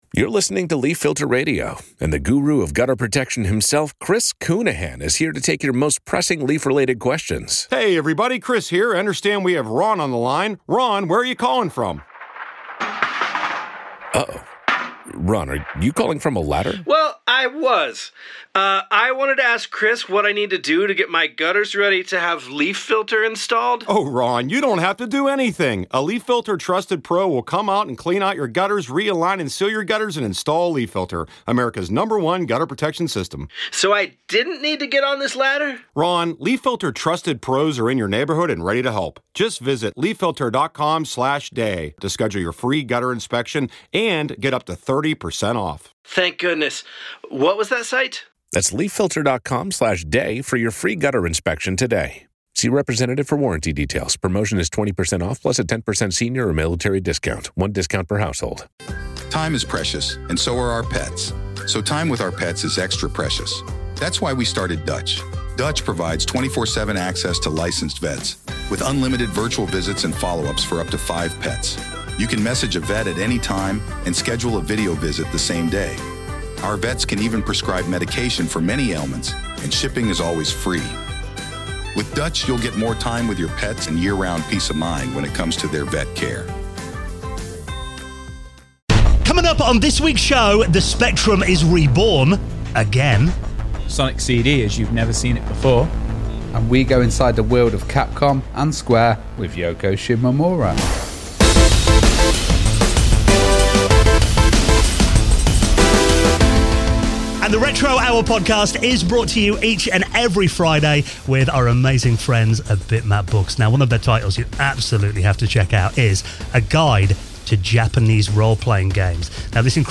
We explore her incredible career and the creative process behind these timeless scores. Recorded live at RetroMessa in Norway.